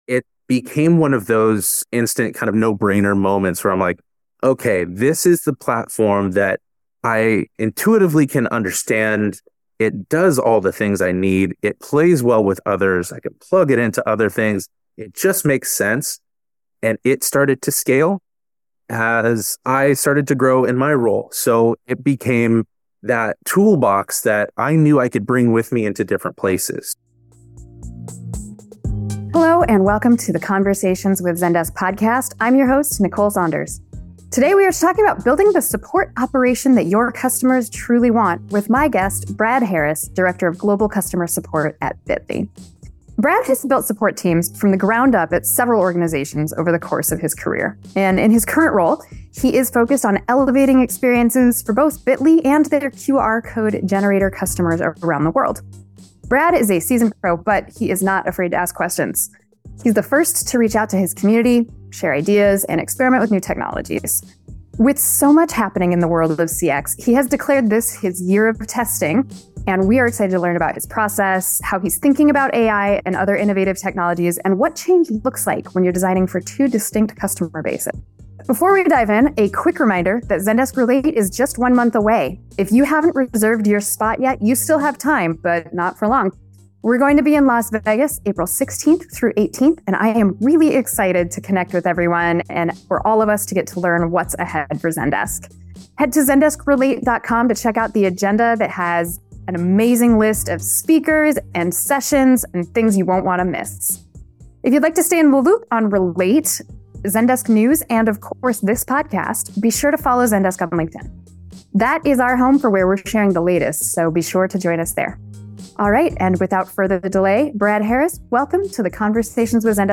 Conversations with Zendesk - Interviews about Customer Service, Support, and Customer Experience